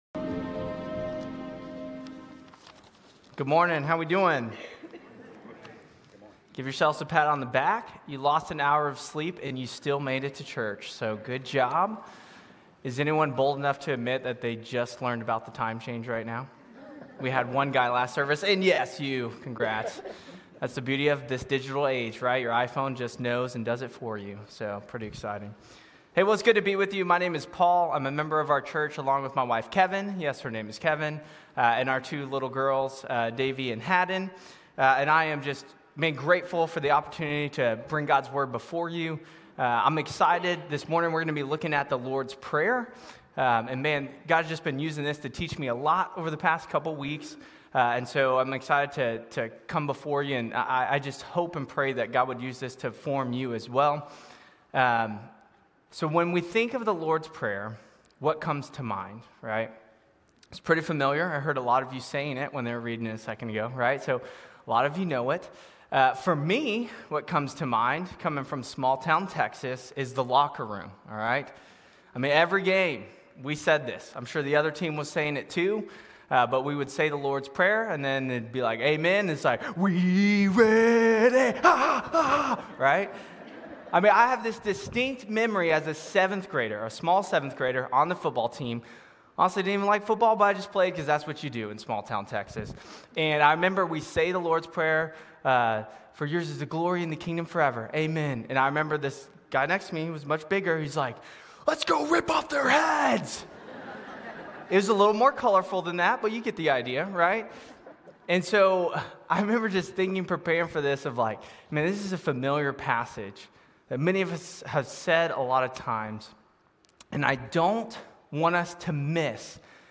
Grace Community Church Lindale Campus Sermons 3_9 Lindale Campus Mar 10 2025 | 00:30:46 1x 00:00 / 00:30:46 Subscribe Share RSS Feed Share Link Embed